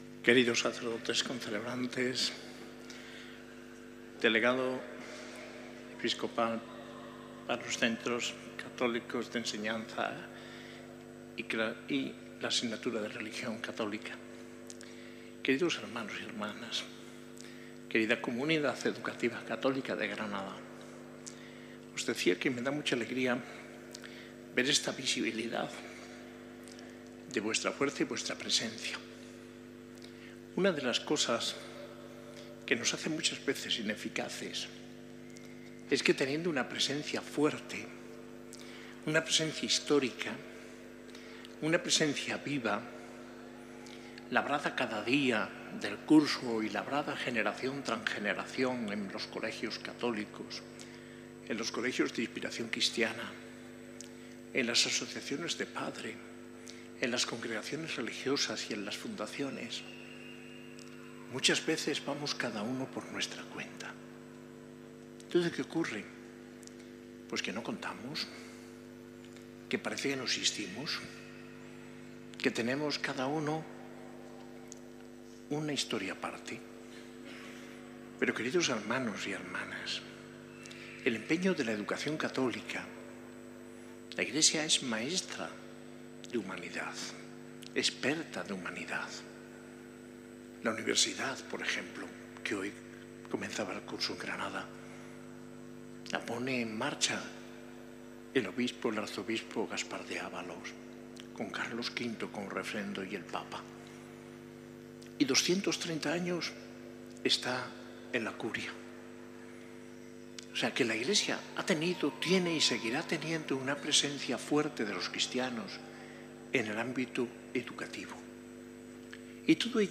Homilía en la Eucaristía de inicio de curso de la Delegación de Educación Católica y Enseñanza Religiosa, el 13 de septiembre de 2024, en la S.A.I Catedral de Granada.